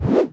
sfx_throw.wav